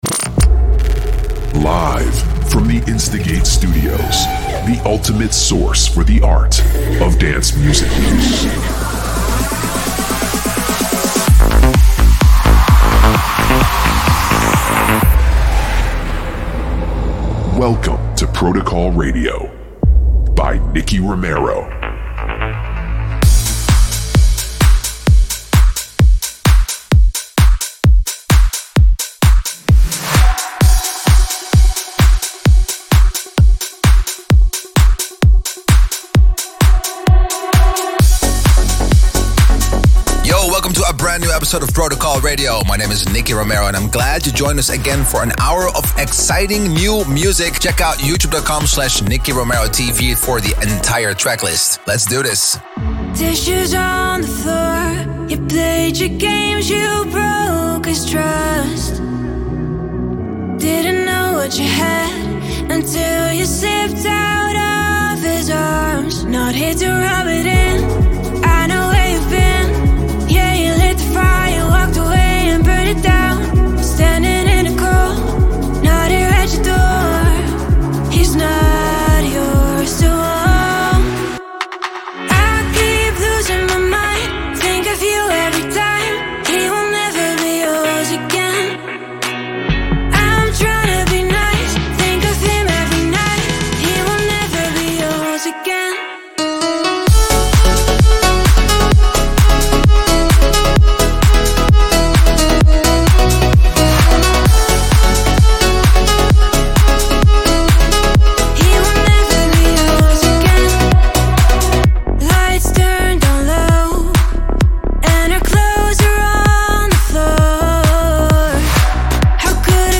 music DJ Mix in MP3 format
Genre: Electro Pop